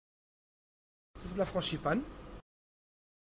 uitspraak La frangipane